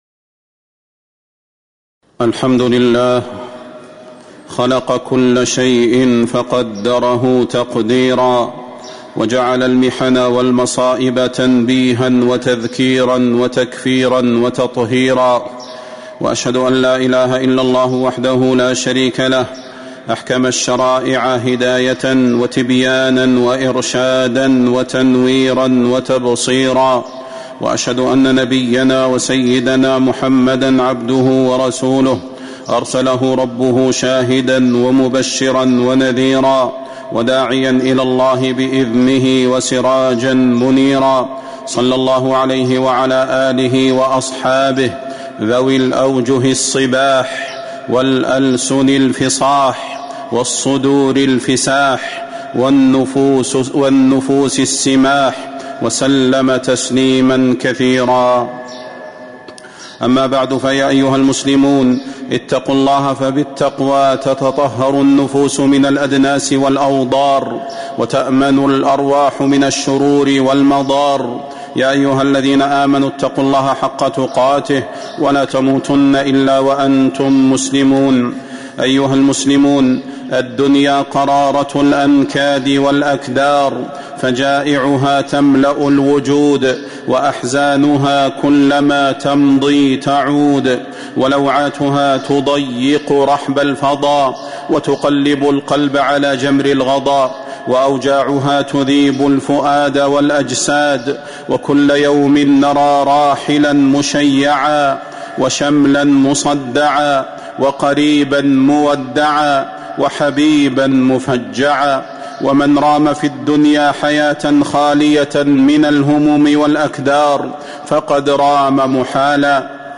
تاريخ النشر ٨ شعبان ١٤٤٦ هـ المكان: المسجد النبوي الشيخ: فضيلة الشيخ د. صلاح بن محمد البدير فضيلة الشيخ د. صلاح بن محمد البدير وبشر الصابرين